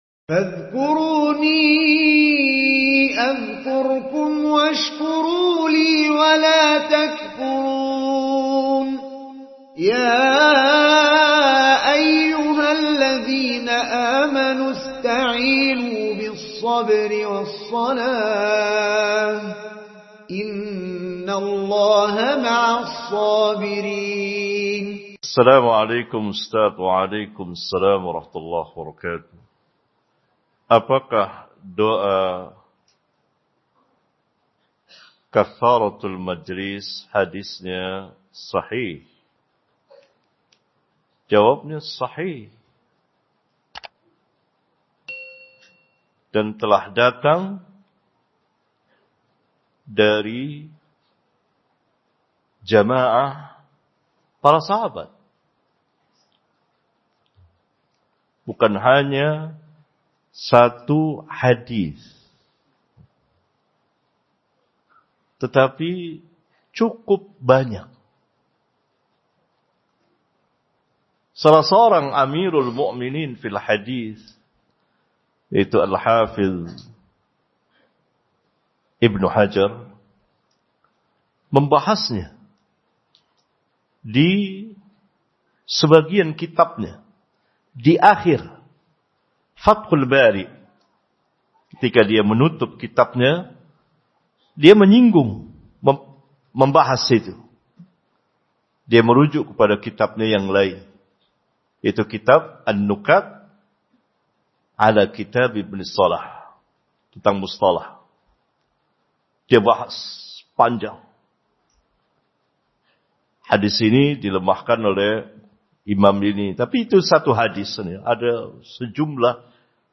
30-menit-tanya-jawab-manhaj-salaf.mp3